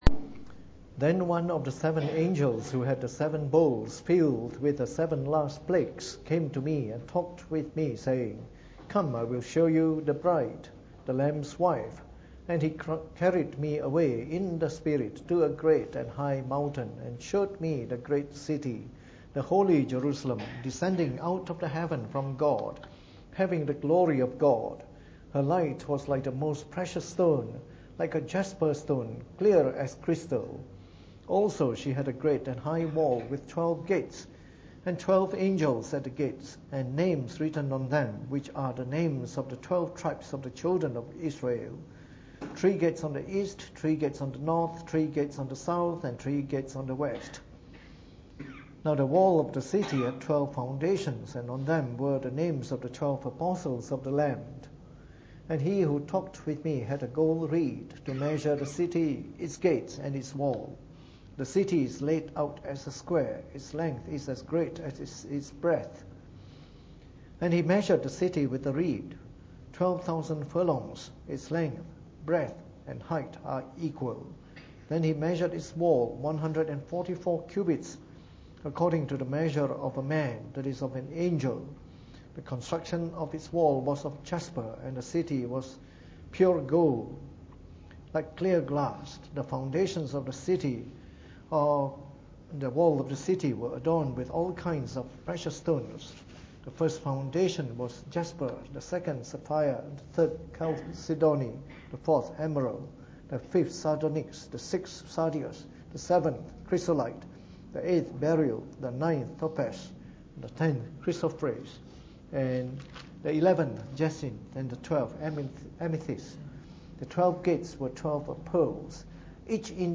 Preached on the 17th of May 2017 during the Bible Study, from our series on Reformed Baptist Churches.